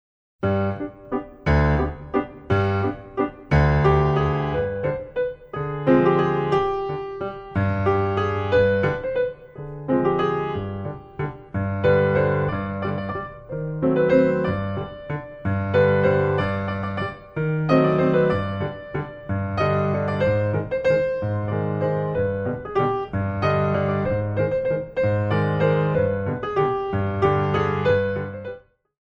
Big Waltz
Traditional